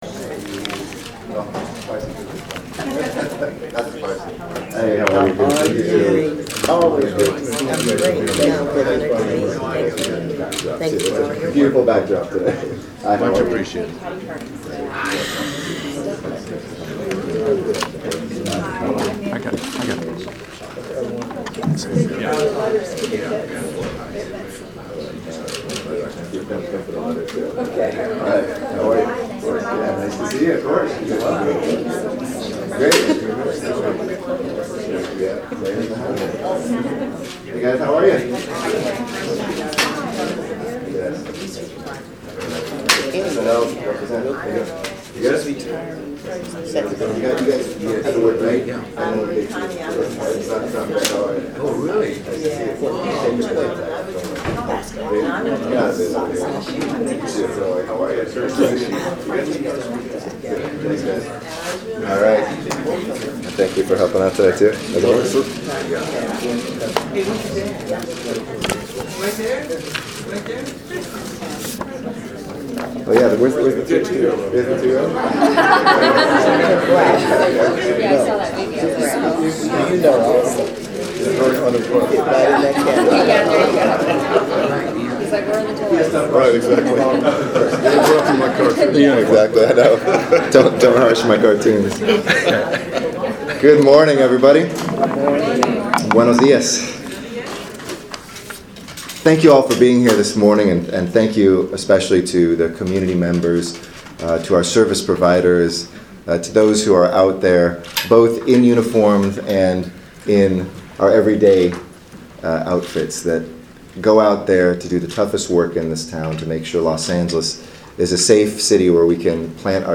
mid-year-crime-stats-news-conf-july-2015.mp3